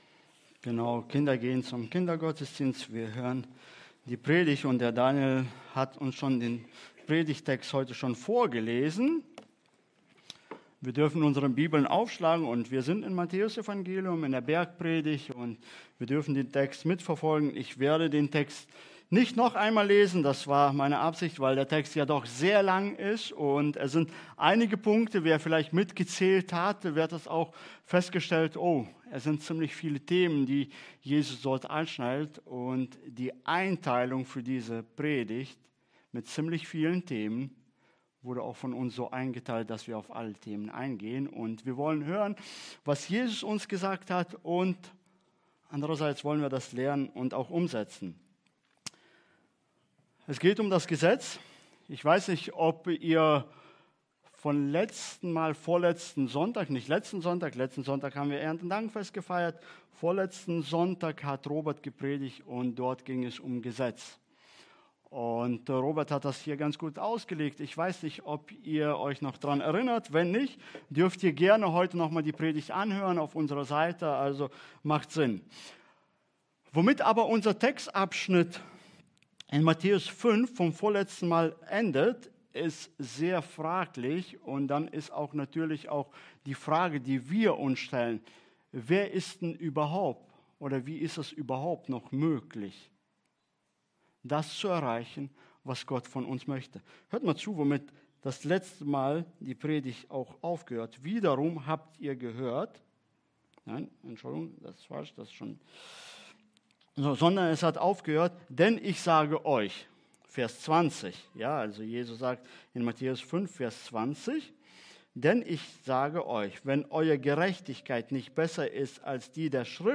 Prediger